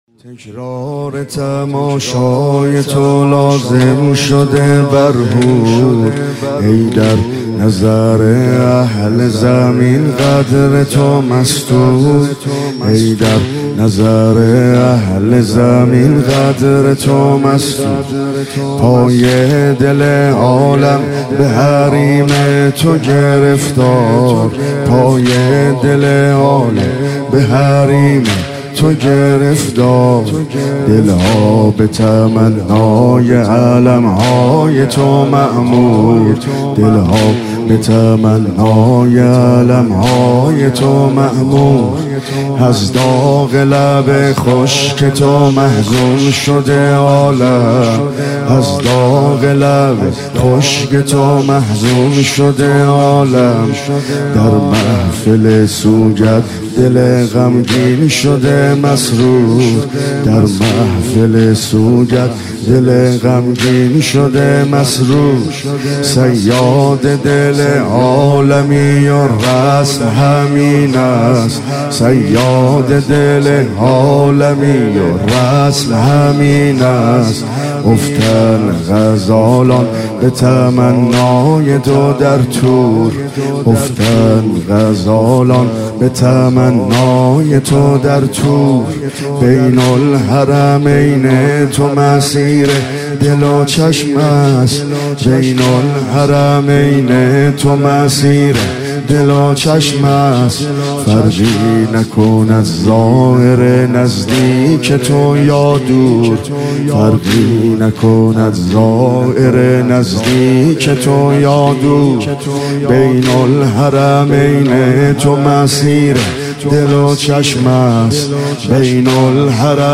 دانلود جدیدترین و گلچین بهترین مداحی های محرم